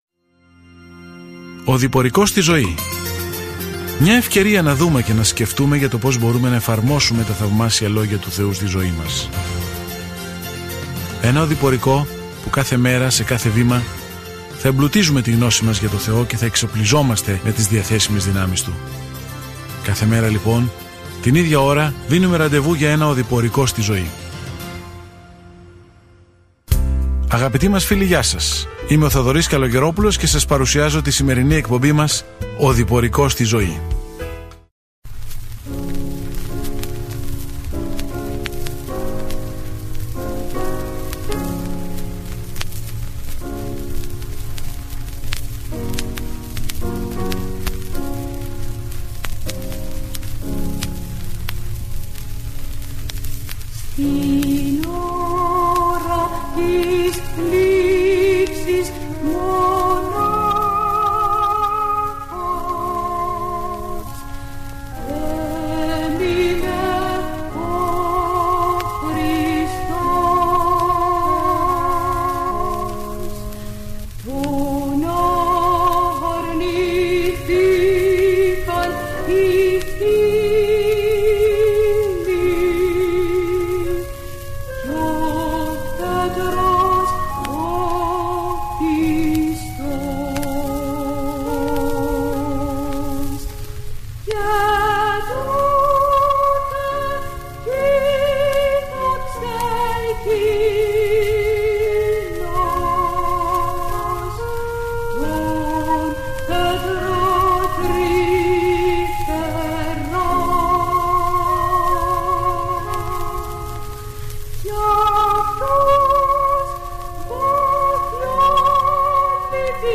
Κείμενο Α΄ ΠΡΟΣ ΤΙΜΟΘΕΟΝ 1:8-18 Ημέρα 3 Έναρξη αυτού του σχεδίου Ημέρα 5 Σχετικά με αυτό το σχέδιο Η πρώτη επιστολή προς τον Τιμόθεο παρέχει πρακτικές ενδείξεις ότι κάποιος έχει αλλάξει από τα ευαγγελικά αληθινά σημάδια της ευσέβειας. Καθημερινά ταξιδεύετε στο 1 Τιμόθεο καθώς ακούτε την ηχητική μελέτη και διαβάζετε επιλεγμένους στίχους από το λόγο του Θεού.